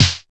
Snaredrum-01.wav